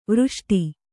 ♪ vřṣṭi